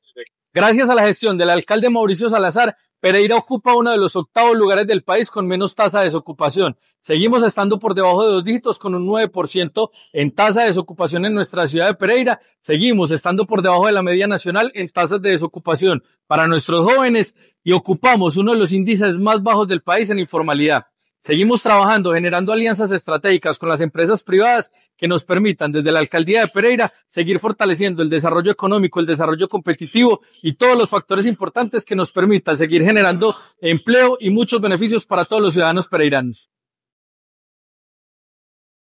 Audio-Cristian-Toro-Sec-Desarrollo-Economico.mp3